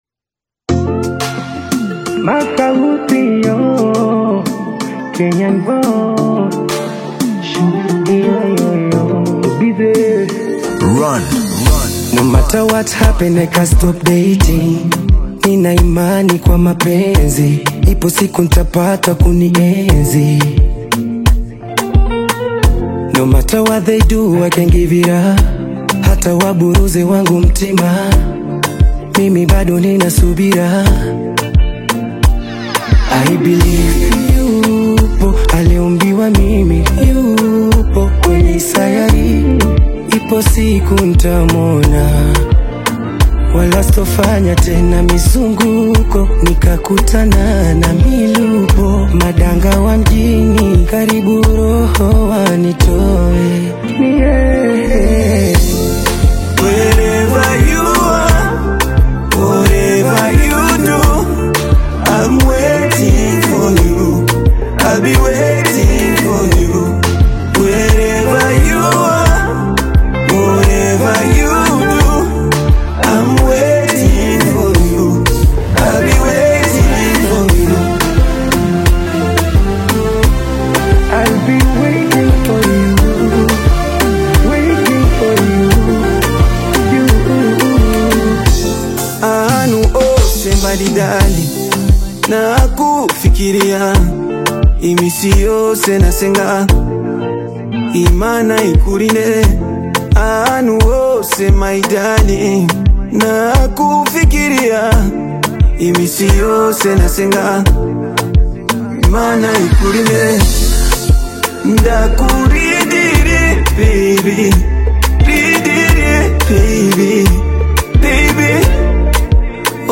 soulful Afro-R&B/Bongo-inspired single
Genre: Afrobeat